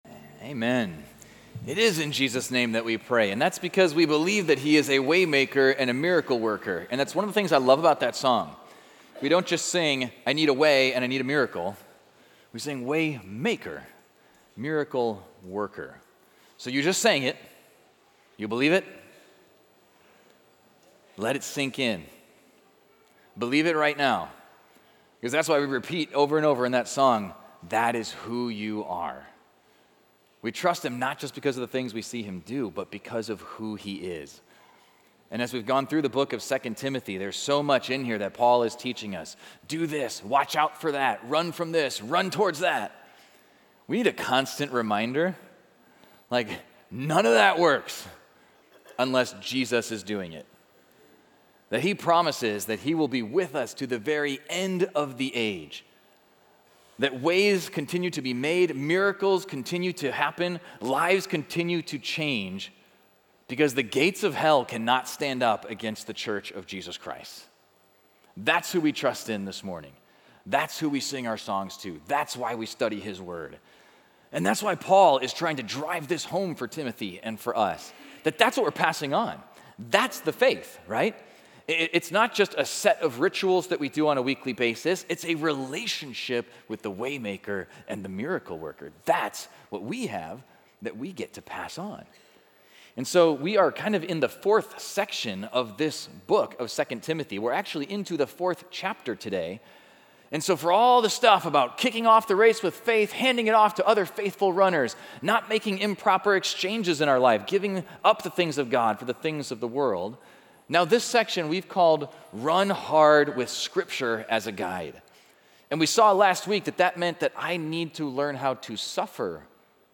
Equipping Service / 2 Timothy: Pass It On / Be an Evangelist. Know Your Ministry.